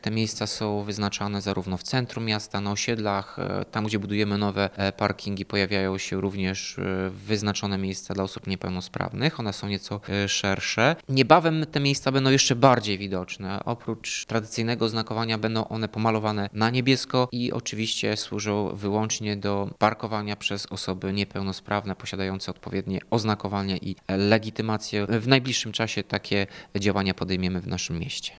Miejsca parkingowe dla niepełnosprawnych – bo o nich mowa – niebawem zmienią swój kolor na niebieski. – Związane jest to ze zmianami Rozporządzenia Ministra Infrastruktury i Rozwoju z 2015 roku – mówi Tomasz Andrukiewicz, prezydent Ełku.